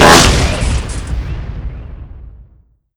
teleporter_explode.wav